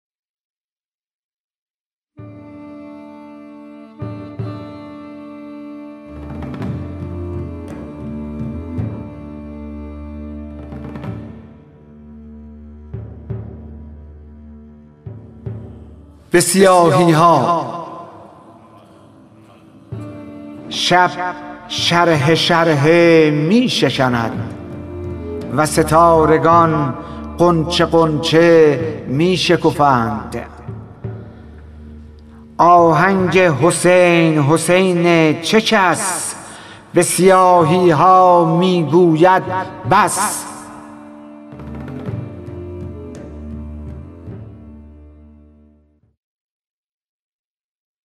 خوانش شعر سپید عاشورایی / ۳